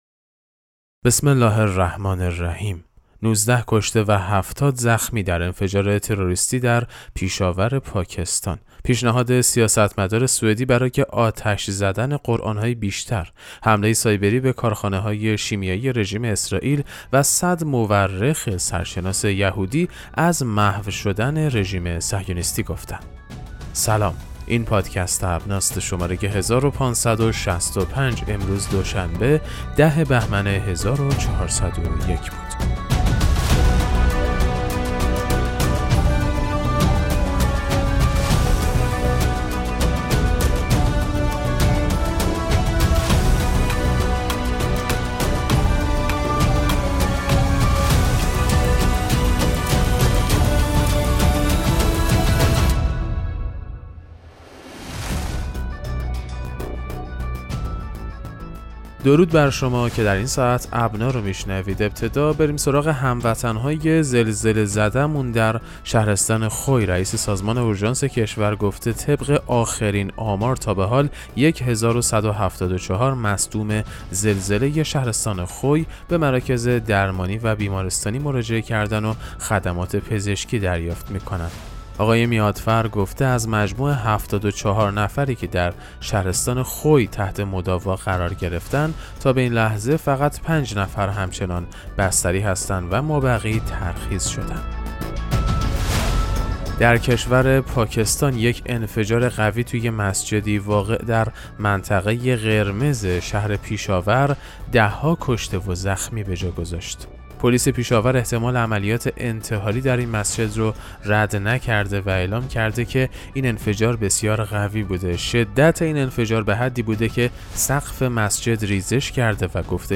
پادکست مهم‌ترین اخبار ابنا فارسی ــ 10 بهمن 1401